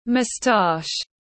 Moustache /məˈstɑːʃ/